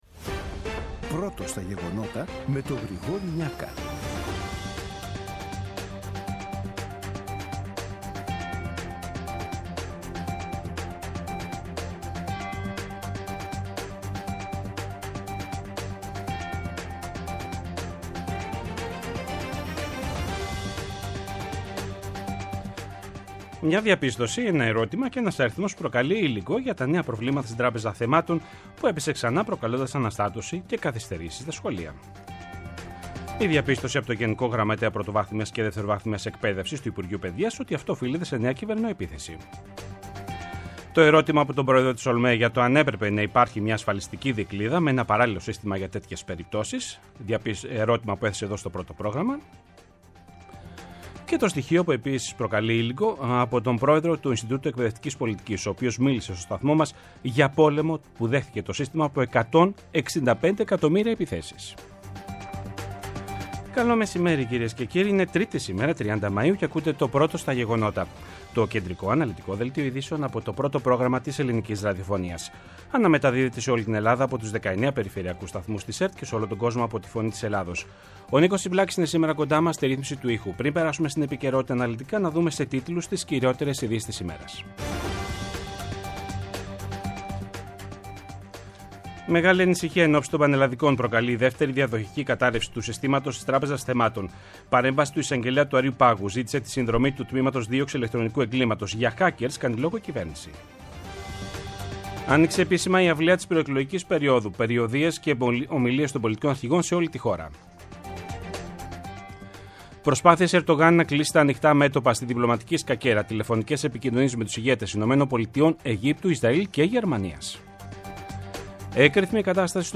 Το κεντρικό ενημερωτικό μαγκαζίνο του Α΄ Προγράμματος, από Δευτέρα έως Παρασκευή στις 14.00. Με το μεγαλύτερο δίκτυο ανταποκριτών σε όλη τη χώρα, αναλυτικά ρεπορτάζ και συνεντεύξεις επικαιρότητας.